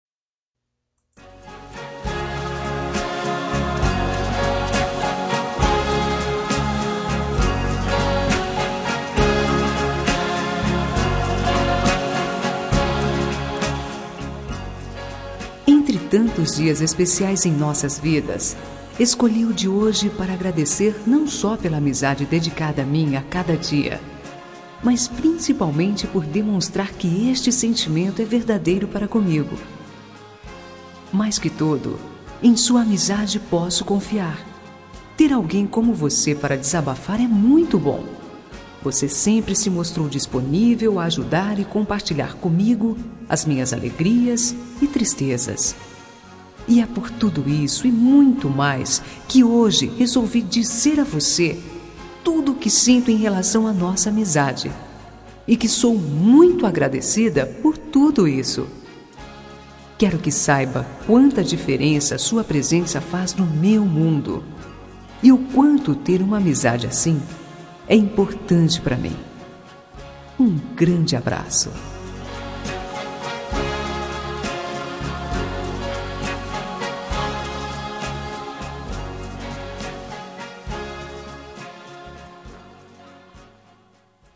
Telemensagem de Amizade – Voz Feminina – Cód: 98
98-amizade-fem.m4a